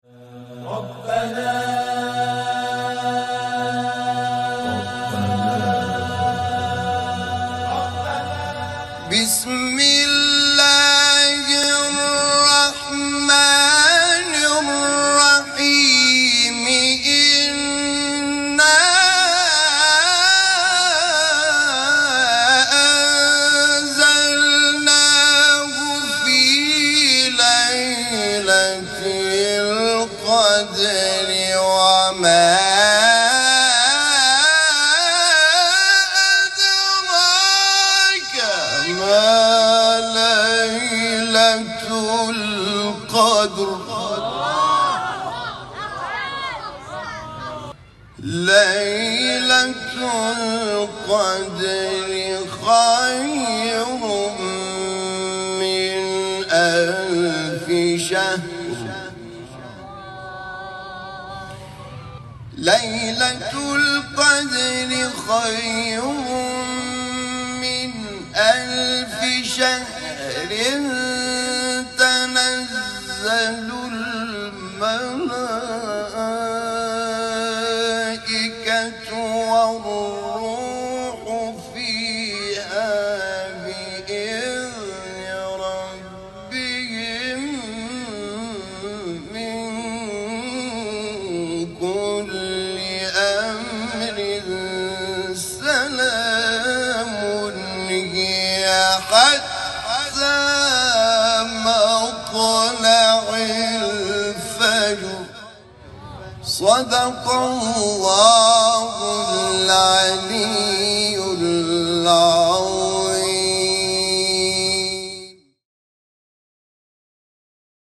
Recitación y traducción de la Sura Al-Qadr
recitación del corán